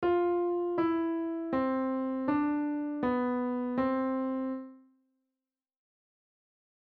On the piano, play The Grand Old Duke Of York